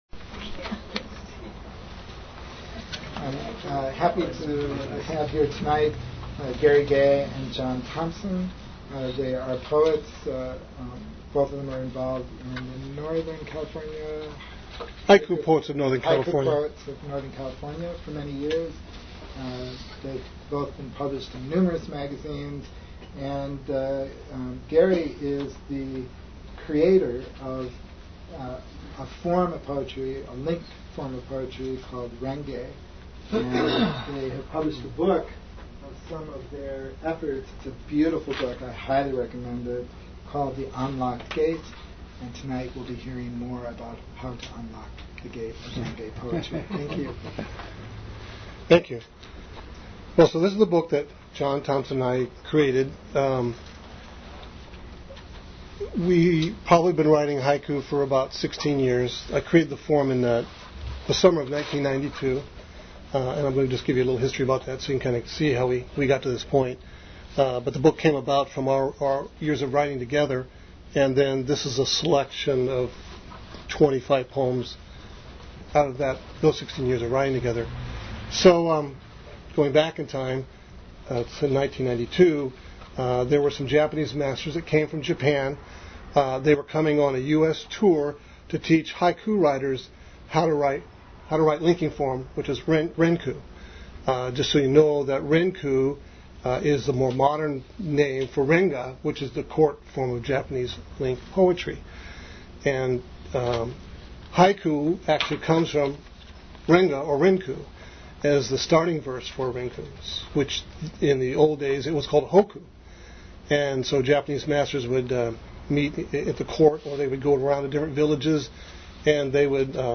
Archive of an event at Sonoma County's largest spiritual bookstore and premium loose leaf tea shop.
The poets will share their views and experiences in crafting these poems together for the past 16 years. In particular they will focus on how rengay and linking verses engage their authors in a poetic dialogue.